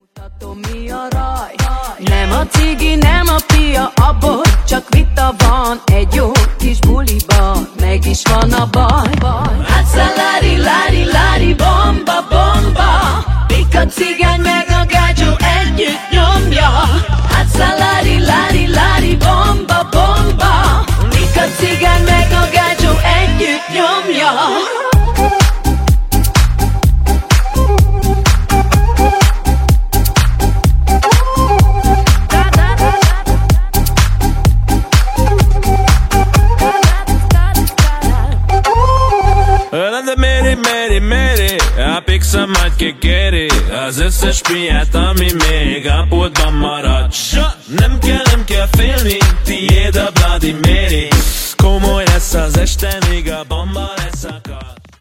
Kategória: Magyar
Minőség: 320 kbps 44.1 kHz Stereo